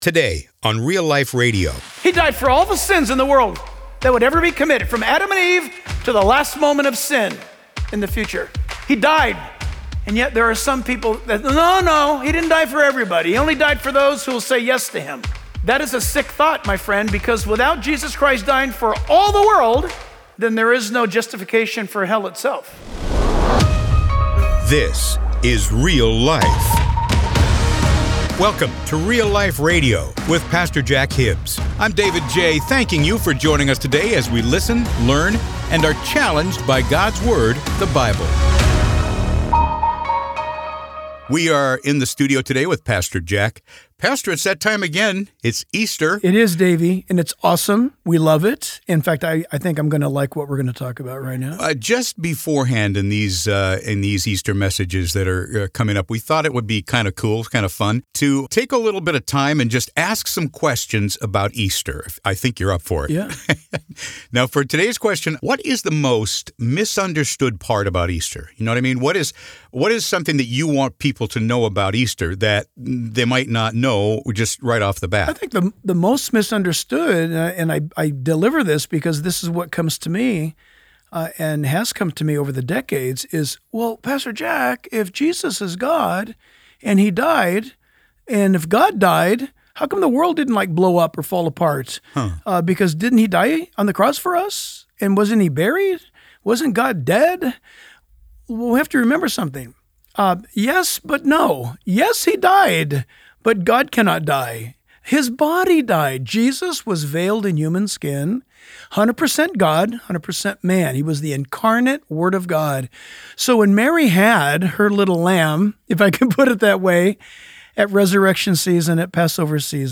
His bold preaching will encourage and challenge you to walk with Jesus.